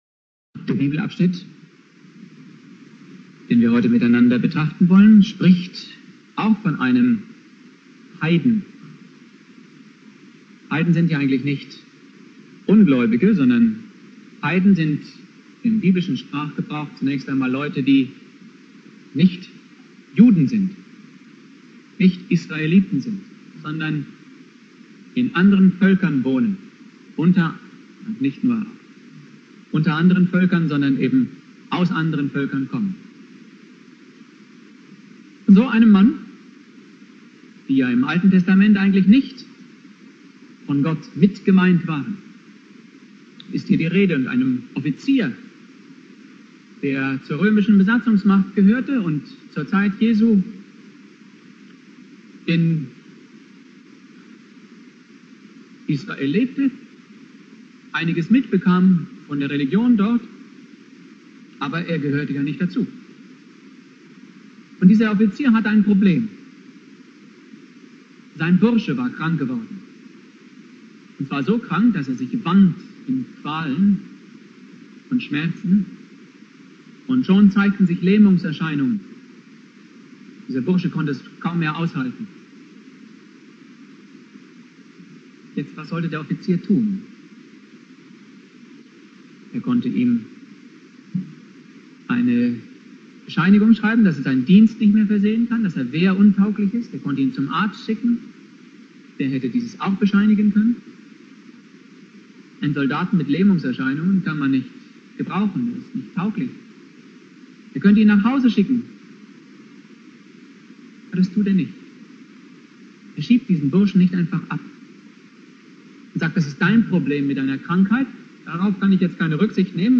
Predigt
1.Weihnachtstag